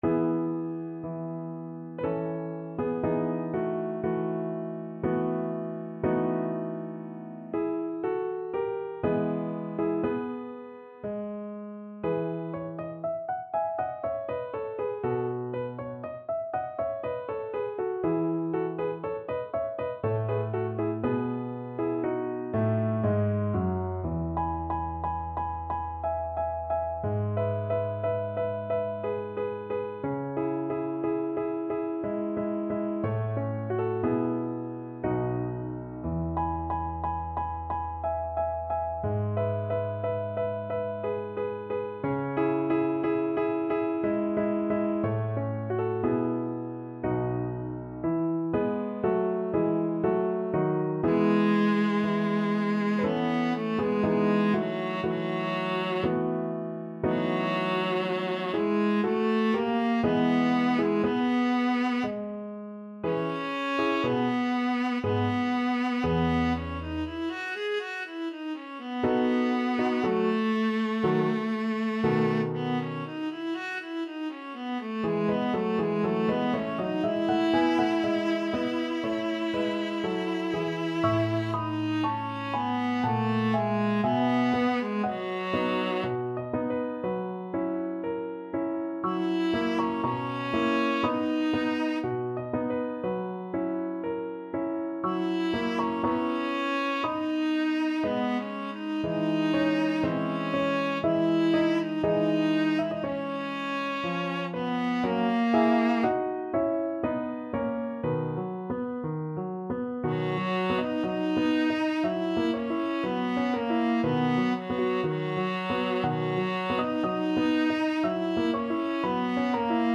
3/4 (View more 3/4 Music)
Classical (View more Classical Viola Music)